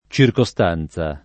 ©irkoSt#nZa] s. f. — negli scrittori dal ’300 al ’600, frequenti variaz. di forma per l’ultima sillaba tra -za e -zia, come pure (anche a proposito di circostante) per le due sillabe di mezzo tra -costan-, -constan-, -custan-, -cunstan-